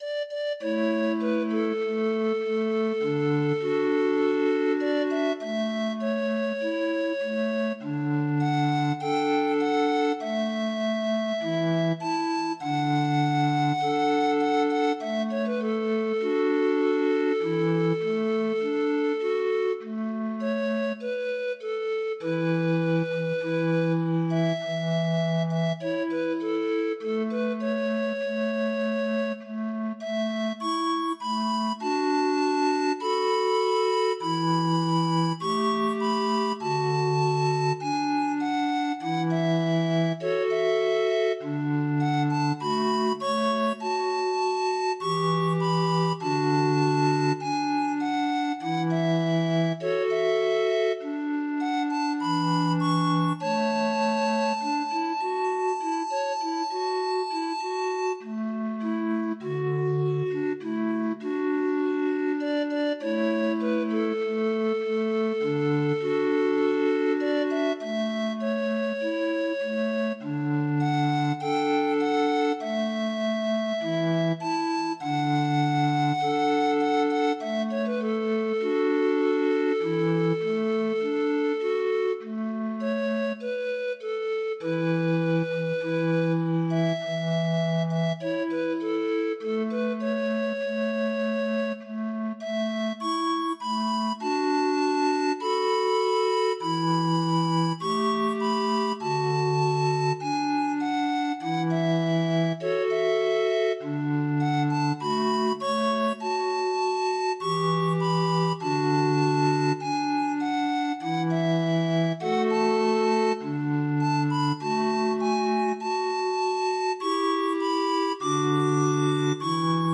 Demo of 25 note MIDI file